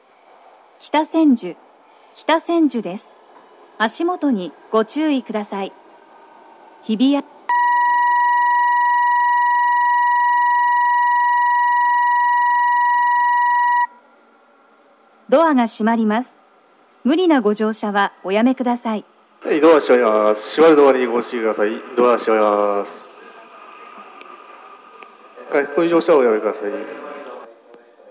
北千住駅　Kita-Senju Station ◆スピーカー：BOSE天井型
1番線発車ブザー